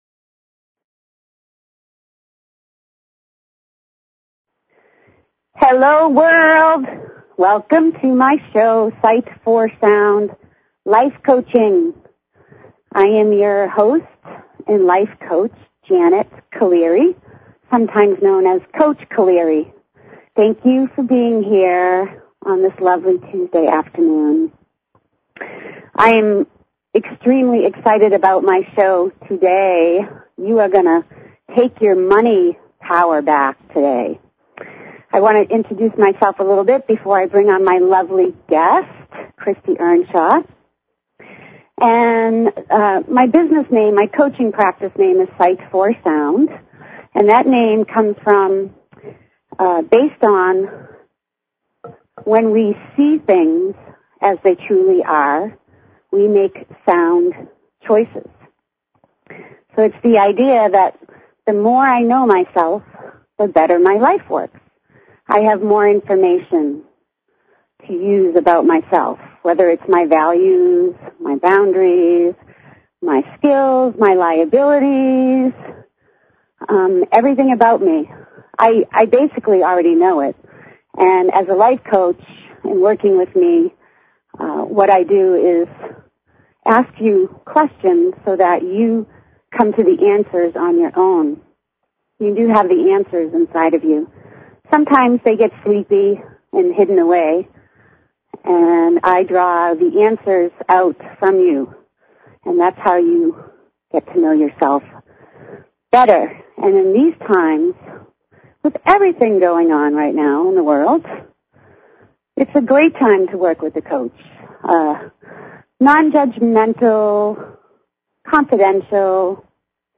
Talk Show Episode, Audio Podcast, Sight_for_Sound and Courtesy of BBS Radio on , show guests , about , categorized as
She encourages you to call in and ask questions or share thoughts!!!